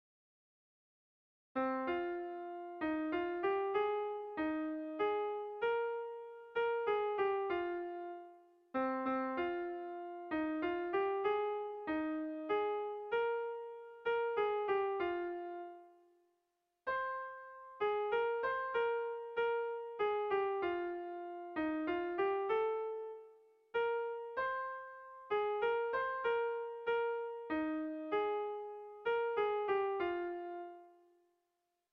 Tragikoa
Neurrian baditu gorabeherak.
Zortziko txikia (hg) / Lau puntuko txikia (ip)
AABD